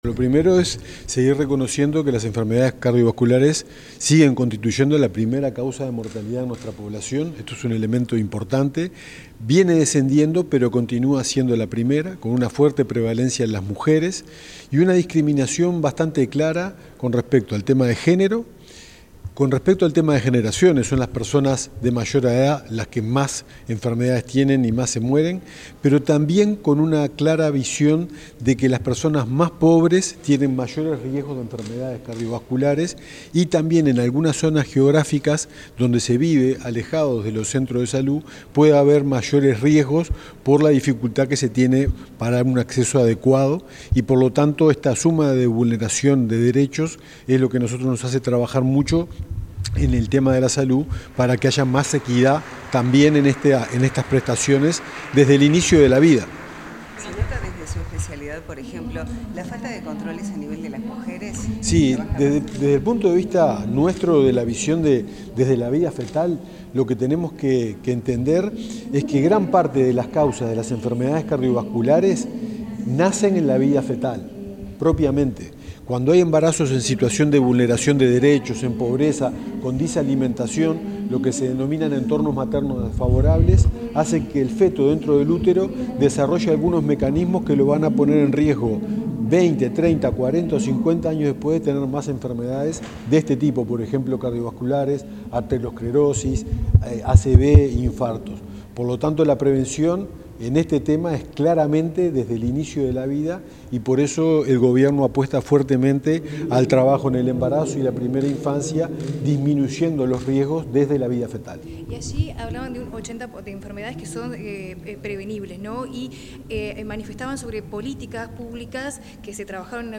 Declaraciones del subsecretario de Salud Pública, Leonel Briozzo
El subsecretario de Salud Pública, Leonel Briozzo, realizó declaraciones en el marco de la 34.ª Semana del Corazón, que se celebra en Uruguay del 22